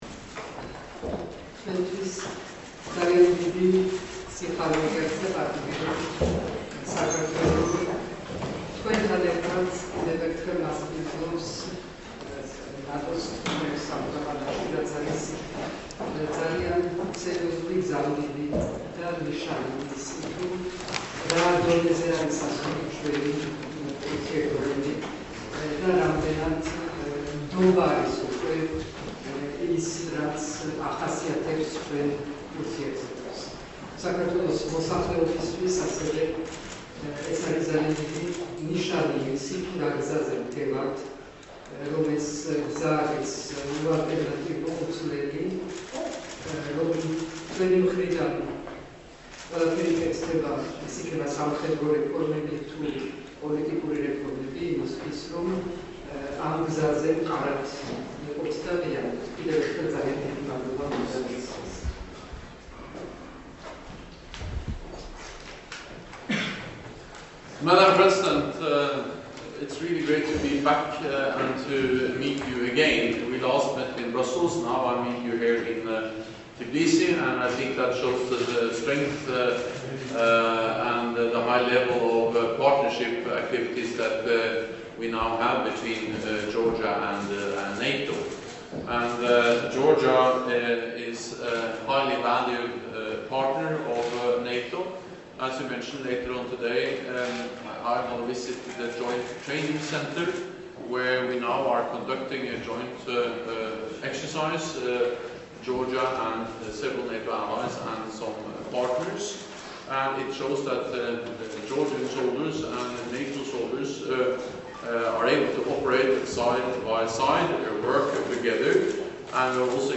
Joint press conference with NATO Secretary General Jens Stoltenberg and the Prime Minister of Georgia, Mamuka Bakhtadze